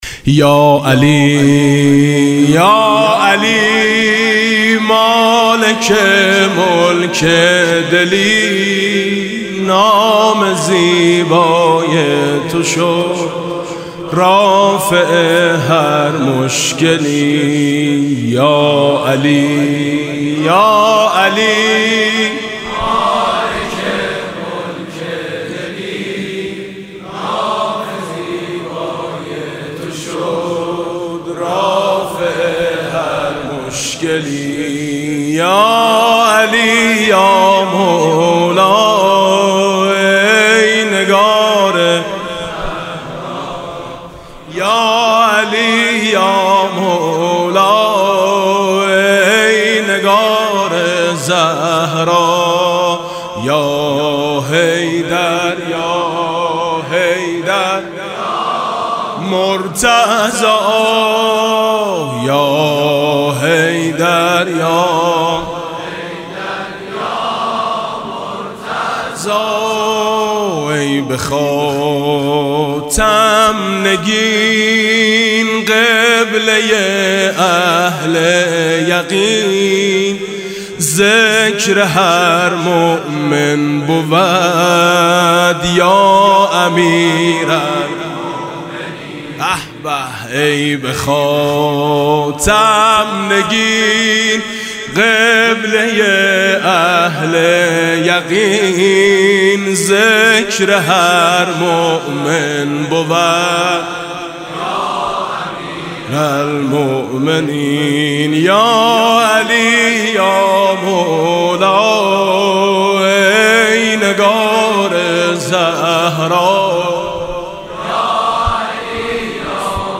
سرود: نام زیبای تو رافع هر مشکلی